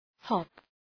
Προφορά
{hɒp}